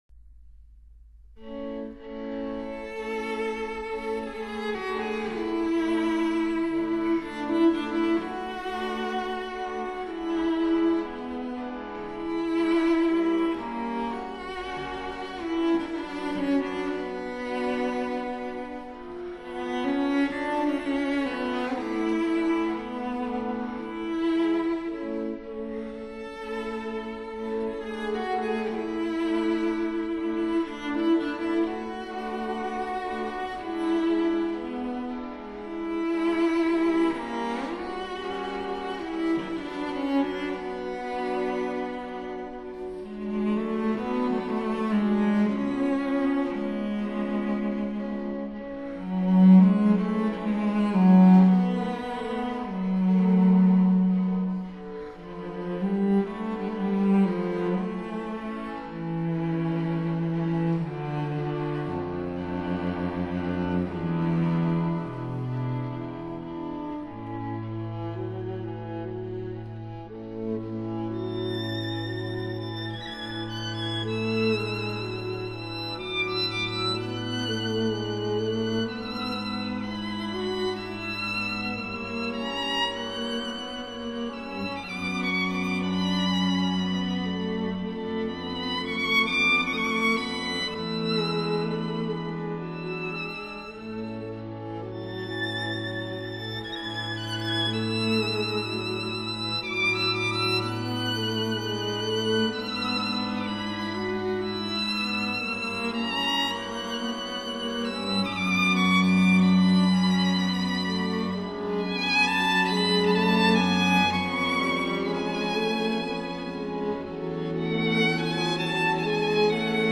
D大調第二號弦樂四重奏
String Quartet No. 2 in D major